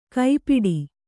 ♪ kai piḍi